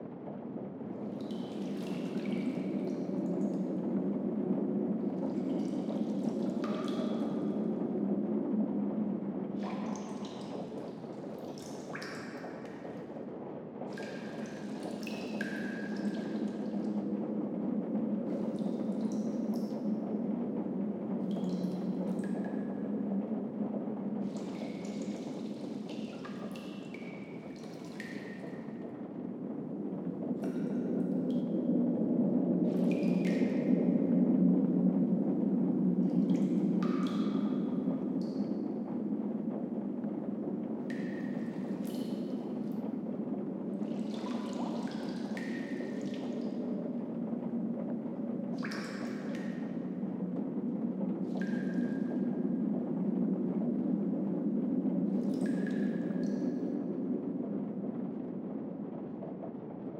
BGS Loops / Cave / Cave Rain.wav
Cave Rain.wav